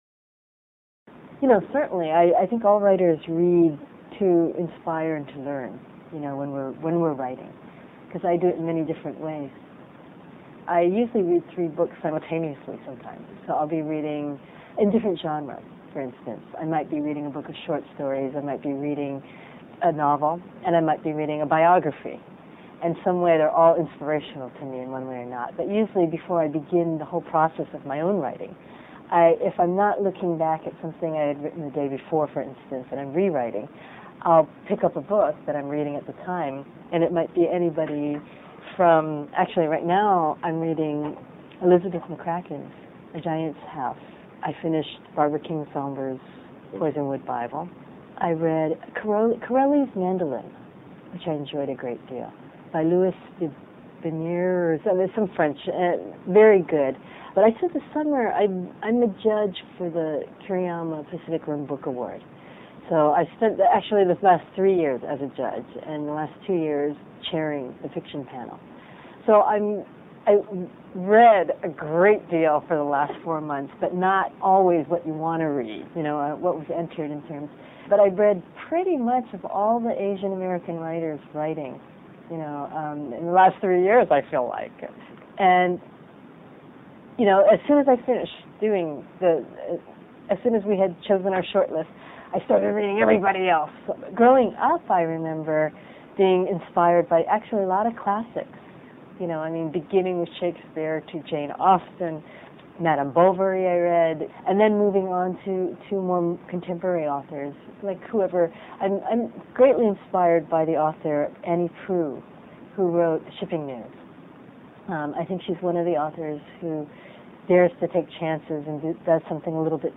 Q&A with Gail Tsukiyama